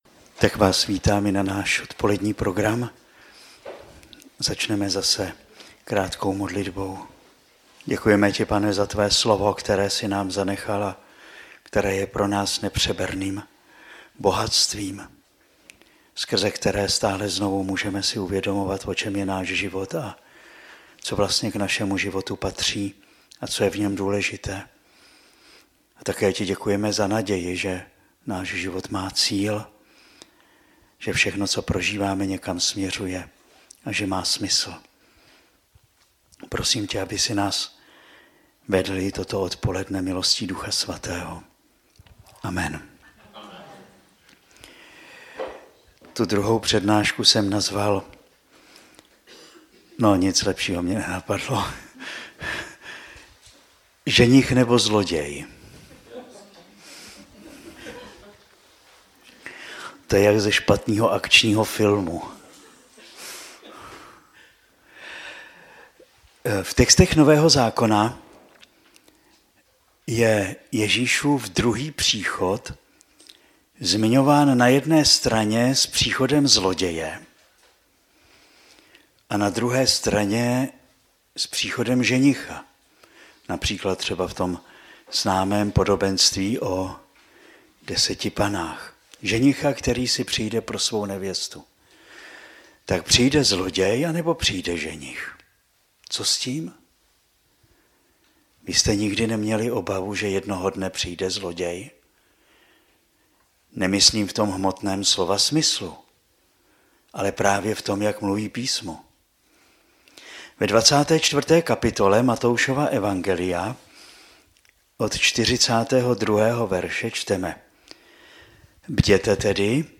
Poslechněte si záznam 2. přednášky
z Adventní duchovní obnovy, která proběhla v sobotu 15.12.2018 ve farnosti Praha - Liboc